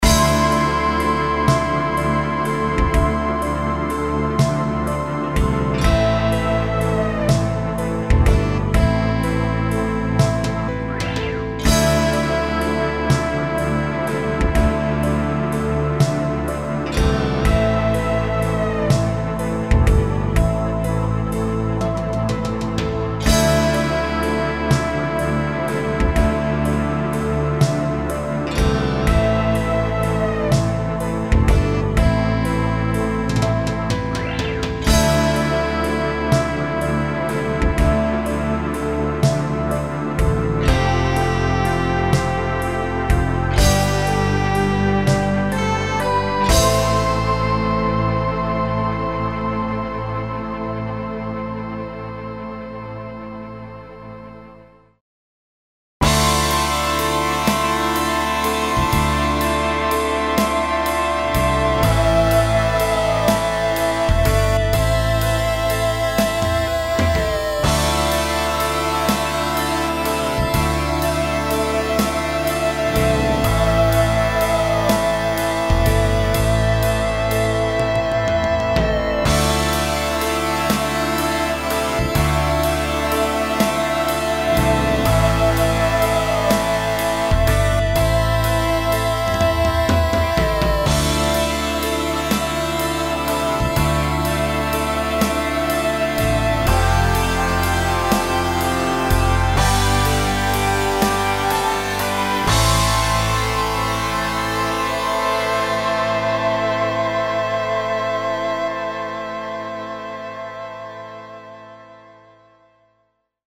Между ними пауза в 1 секунду.. чтоб отдышаться от каки. :-)
НИКАКОЙ дополнительной обработки не производилось.